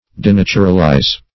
Denaturalize \De*nat"u*ral*ize\ (?; 135), v. t. [imp.